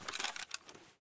sounds / eft_wp / sks / draw.ogg
draw.ogg